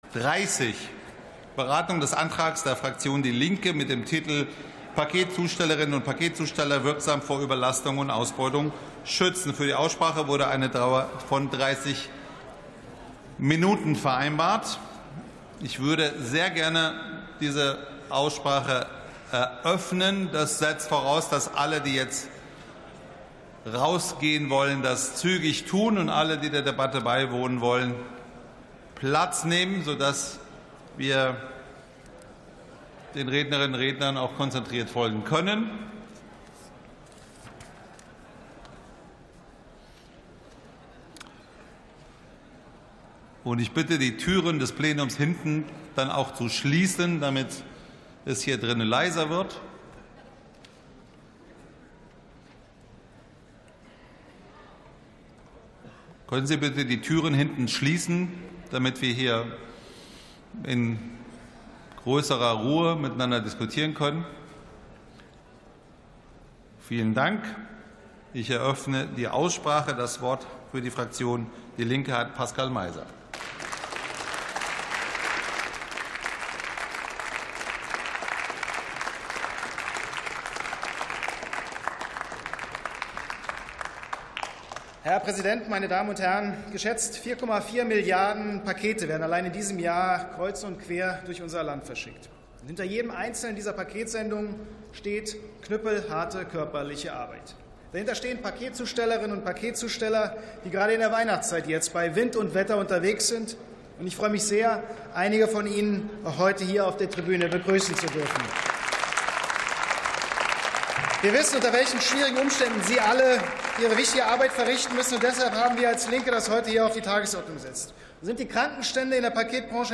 Sitzung vom 05.12.2025. TOP 30: Arbeitsbedingungen in der Paketzustellung ~ Plenarsitzungen - Audio Podcasts Podcast